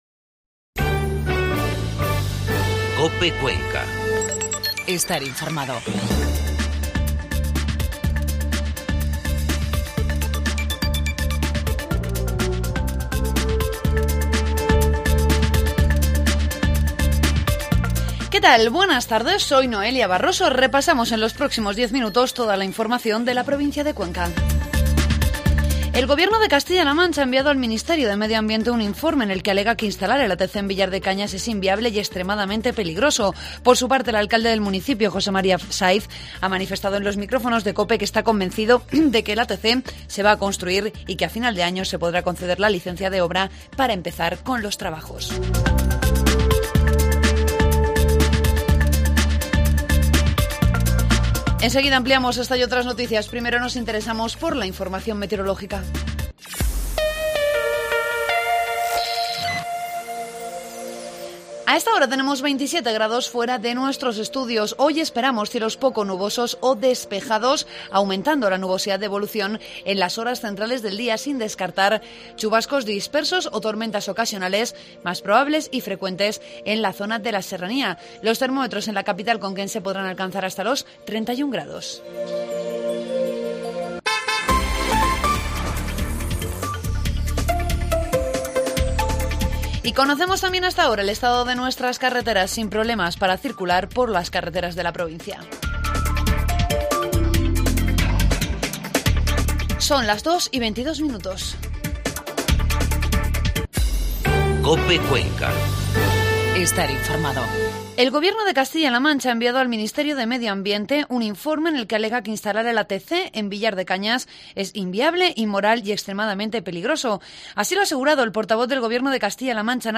Informativo mediodía COPE Cuenca 6 de septiembre